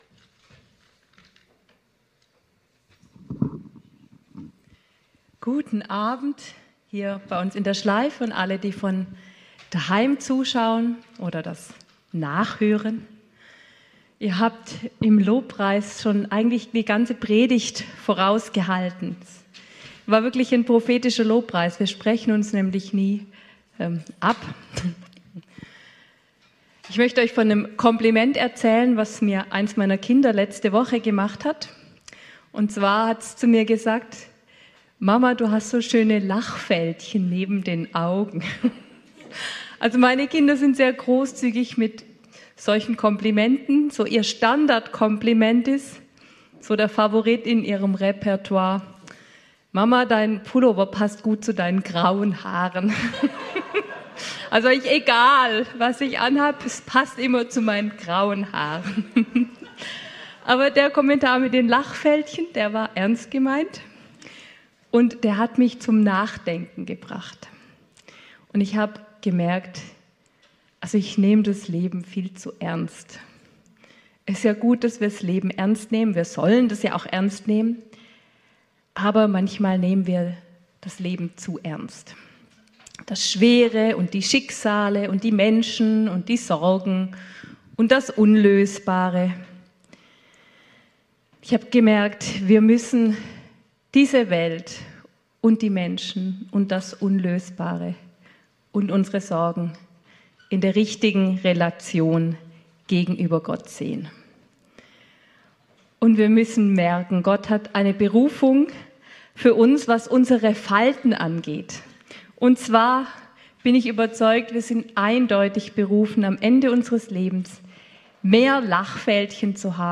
Dienstagsgottesdienst vom 18.